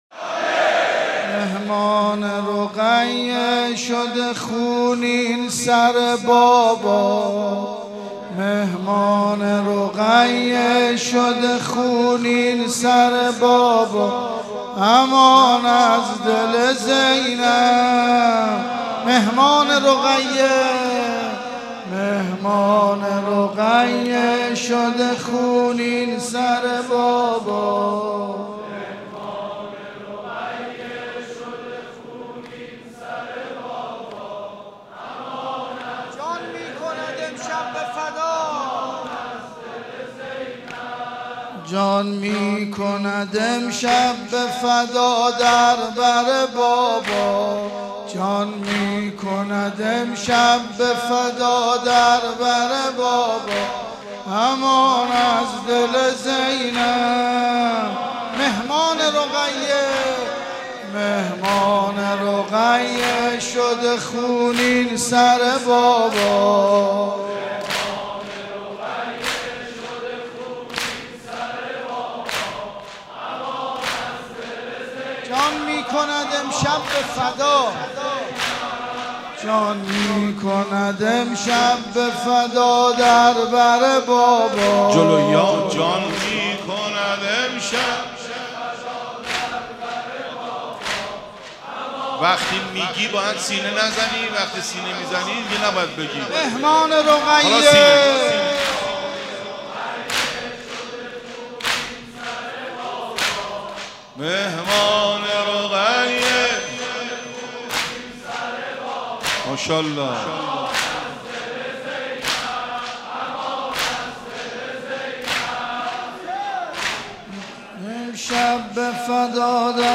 شب سوم محرم - مسجد امیر (ع) - مهر 95